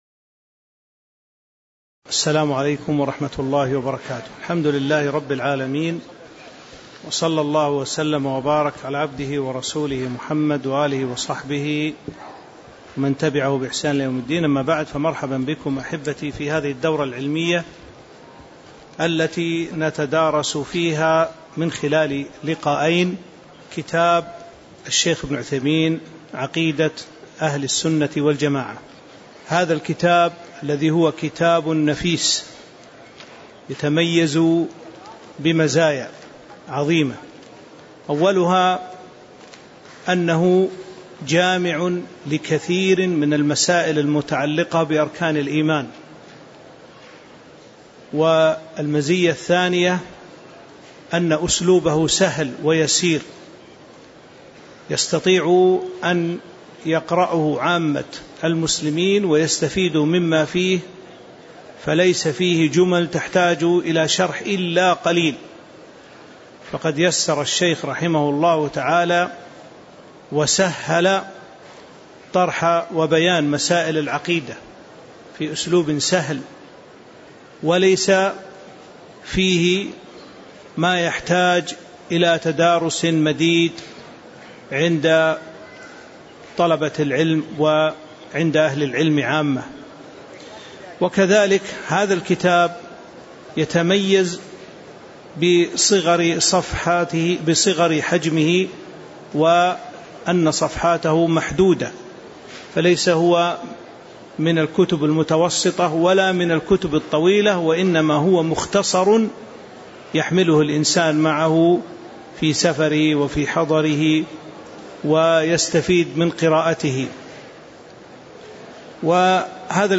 تاريخ النشر ١٦ شعبان ١٤٤٤ هـ المكان: المسجد النبوي الشيخ